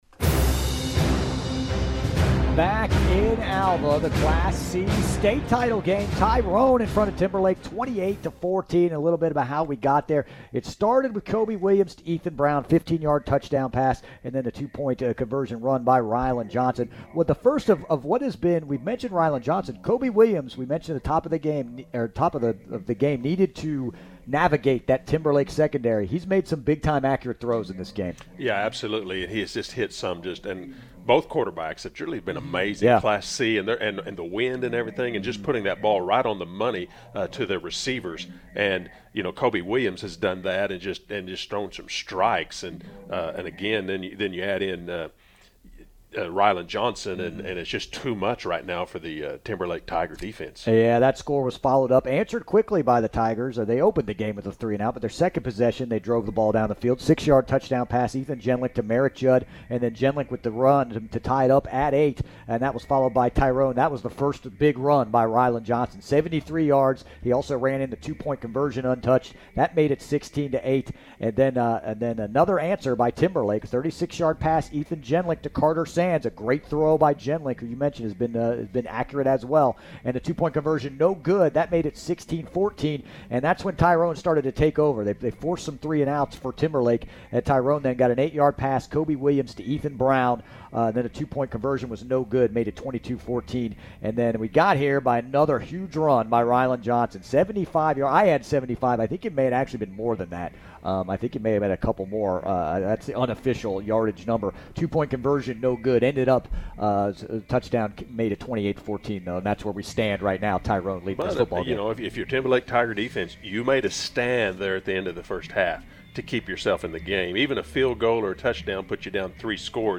You can replay the game right here on K101!